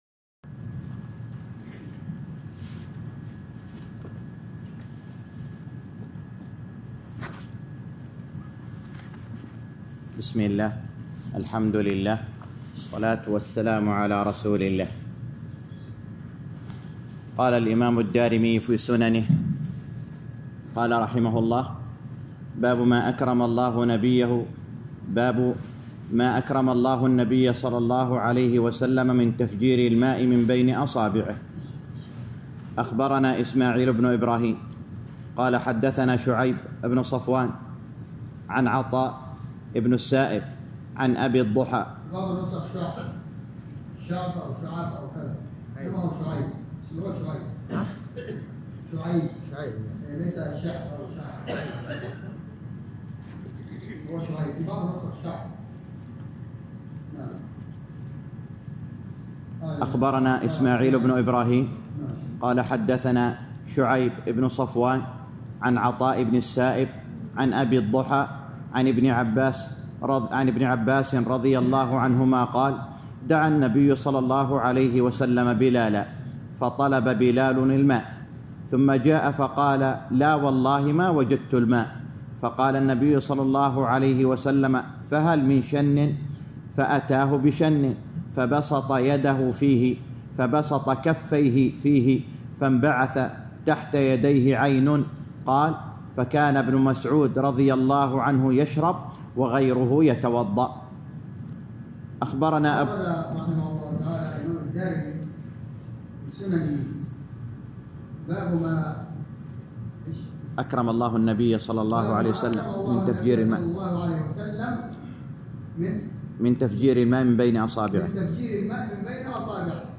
الدرس التاسع - شرح سنن الدارمي الباب الخامس _ 9